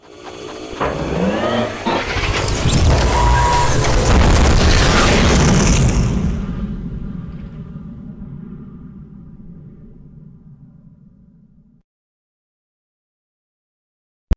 launch.ogg